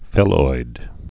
(fĭloid)